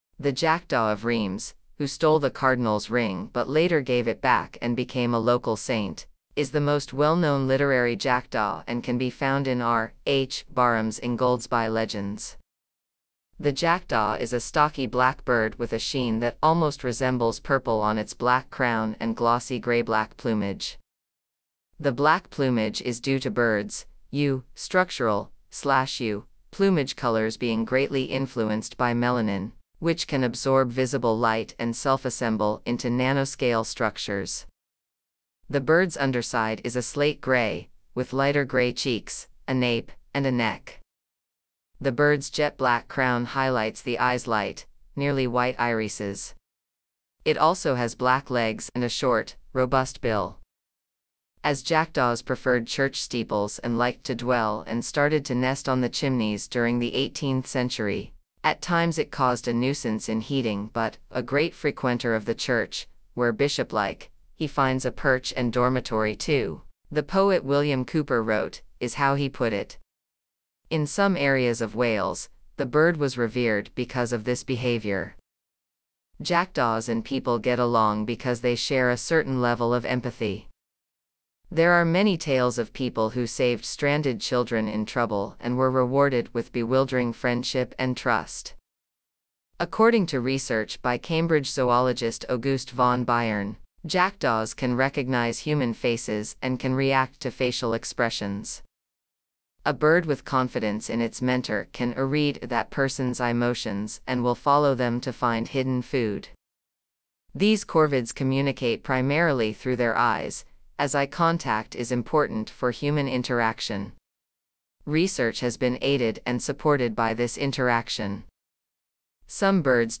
Eurasian Jackdaw
Eurasian-Jackdaw.mp3